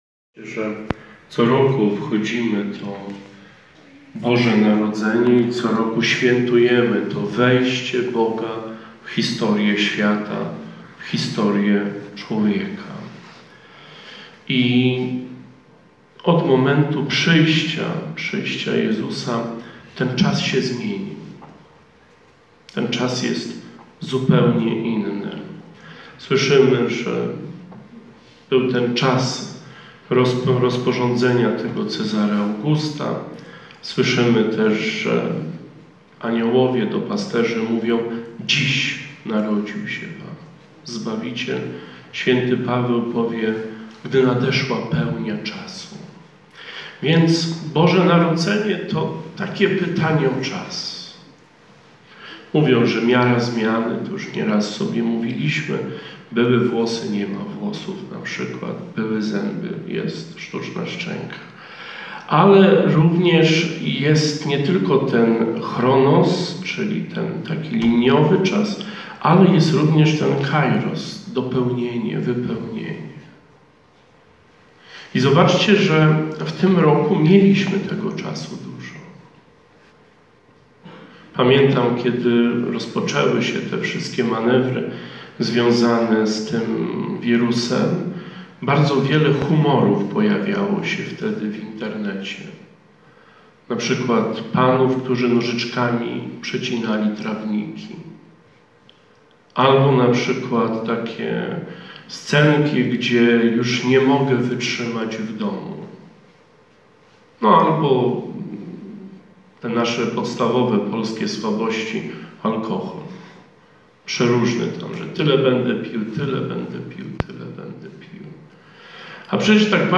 Homilia ks. proboszcza – Pasterka 2020